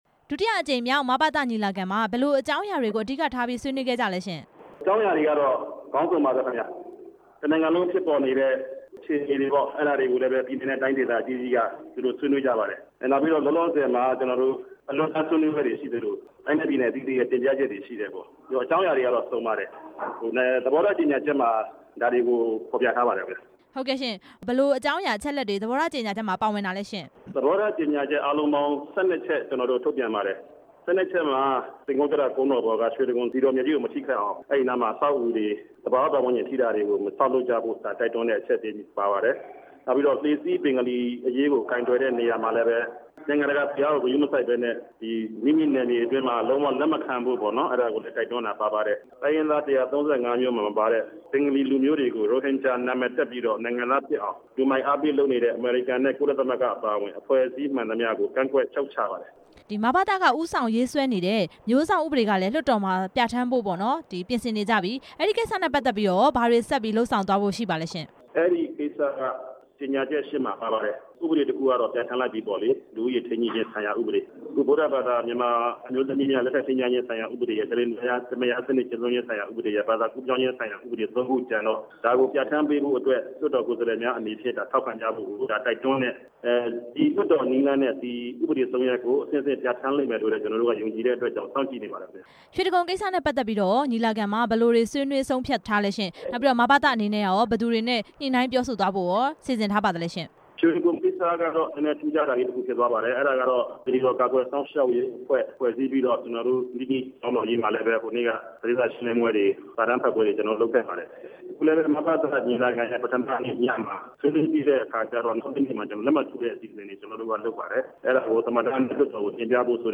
မဘသ ဒုတိယအကြိမ် ညီလာခံ မေးမြန်းချက်